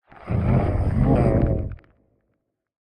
Minecraft Version Minecraft Version 1.21.4 Latest Release | Latest Snapshot 1.21.4 / assets / minecraft / sounds / mob / warden / ambient_8.ogg Compare With Compare With Latest Release | Latest Snapshot
ambient_8.ogg